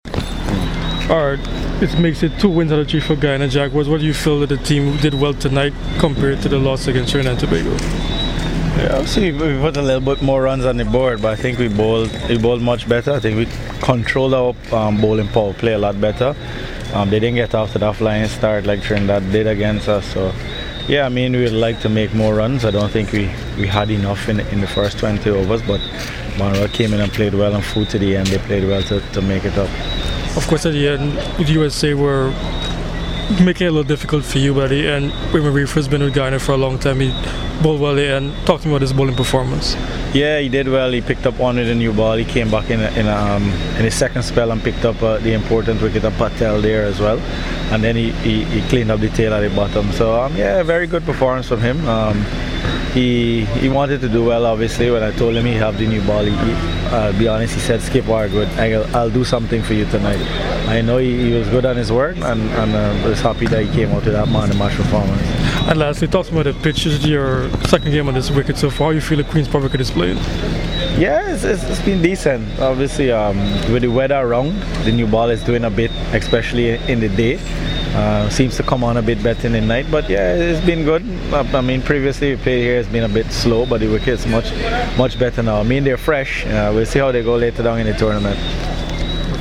Leon Johnson spoke to CWI Media after Zone “B” in the Colonial Medical Insurance Super50 Cup on Monday at Queen's Park Oval and Brian Lara Cricket Academy.